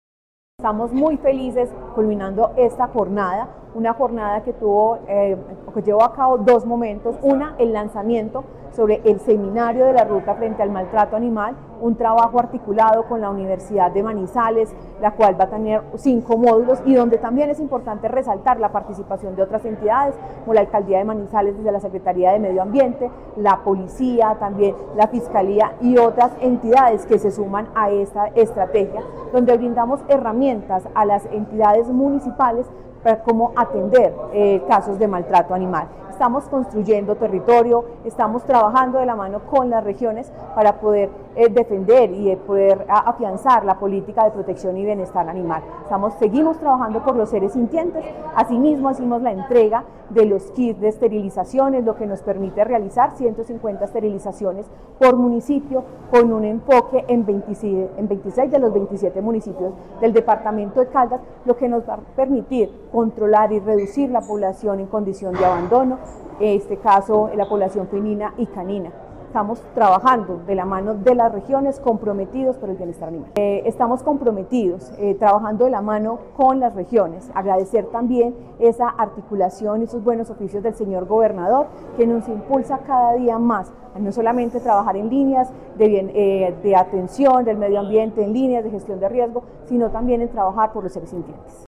Paola Andrea Loaiza, secretaria de Medio Ambiente de Caldas.